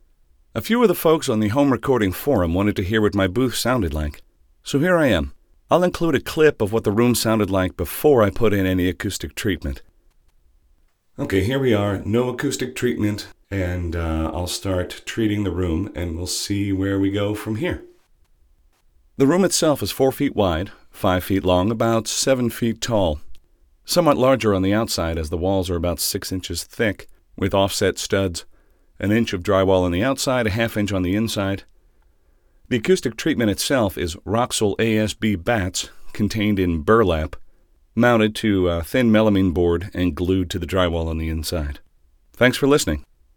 Narration isolation booth
The "pillows" and "cloud" are Roxul ASB in burlap.
I've attached an MP3 with some before and after narration from the booth.
Since I'm only doing straight narration, there is no need for multi-channel mixing, etc. One guy, one mic. I'm using an AT-2020 USB condenser mic directly into a Lenovo G570 laptop.